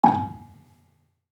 Gambang-G#4-f.wav